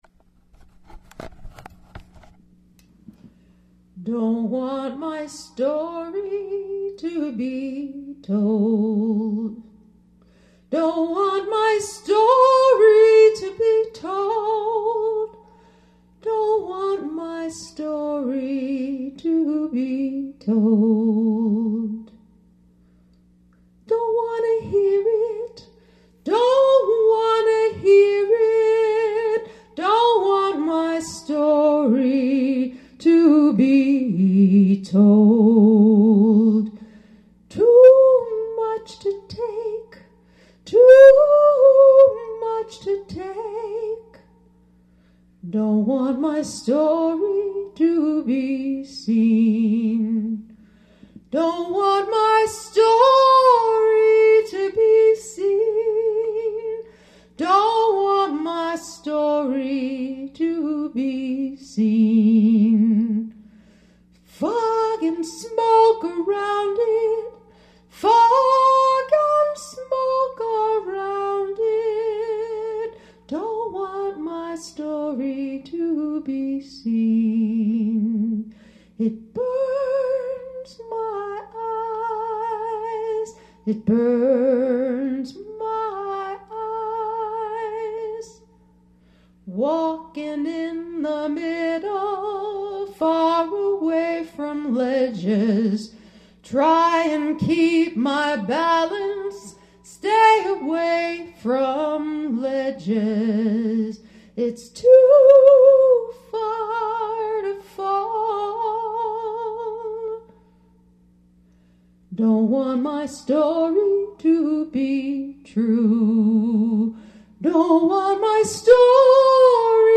Amazing song and soulful version.